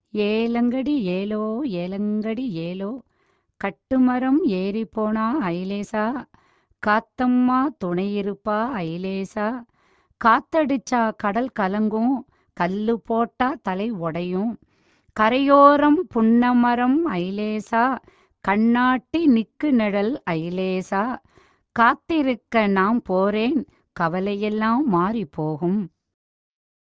திரும்பும் மீனவர்களின் பாடல்களைக்